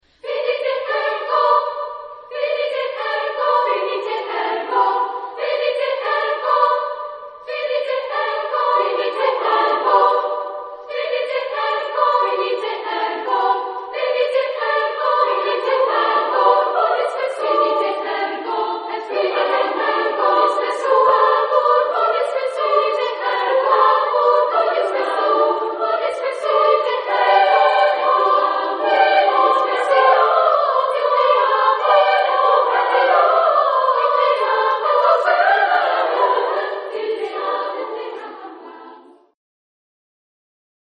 Epoque: 20th century  (1980-1989)
Genre-Style-Form: Sacred ; Prayer
Type of Choir: SSAA  (4 women voices )
Instrumentation: Percussion
Instruments: Handclapping
Tonality: G tonal center ; modal